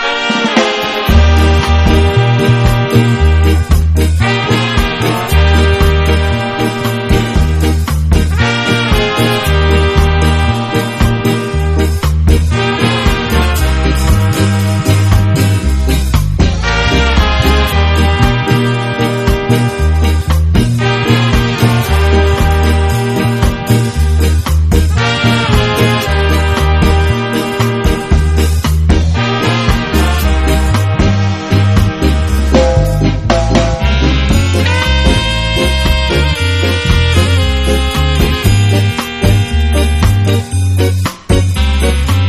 HIP HOP/R&B / JAPANESE (JPN)